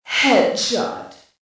Index of /cstrike/sound/female